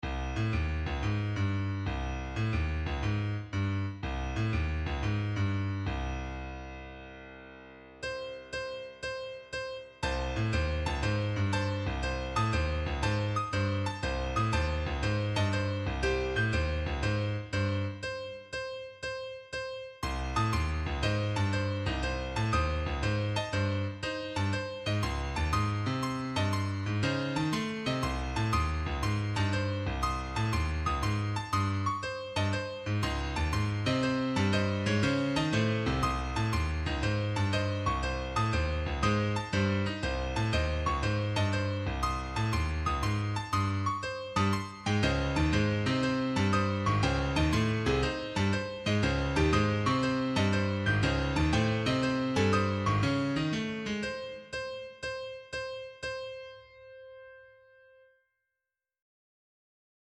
A Sierpiński number is a positive odd integer k such that k · 2n + 1 is composite for all positive integers n. Serialism is a compositional technique wherein various elements of a musical piece (pitches, rhythms, dynamics, etc.) are determined by a central repeating, ordered pattern.
Further, using this source material, we present a serial composition.